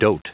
Prononciation du mot dote en anglais (fichier audio)
Prononciation du mot : dote